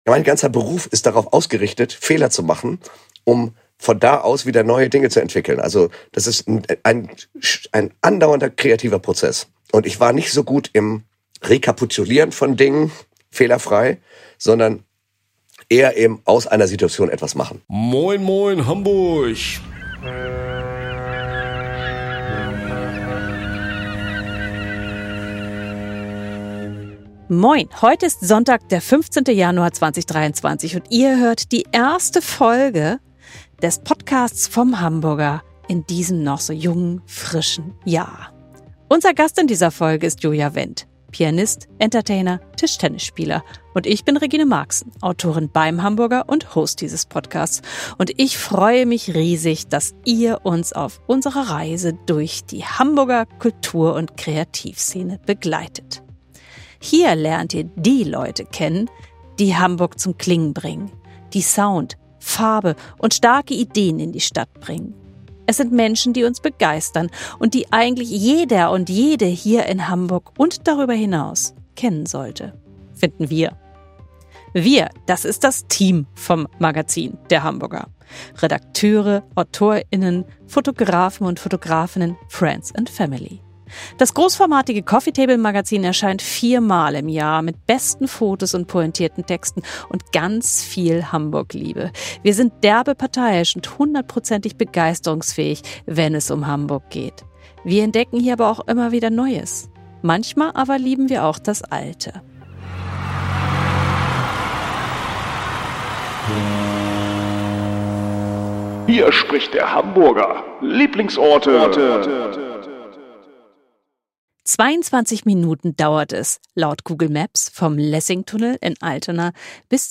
Ein quietschfideles Gespräch (um es mit Joja Wendts Worten zu sagen) über die Lust auf und am Wettbewerb.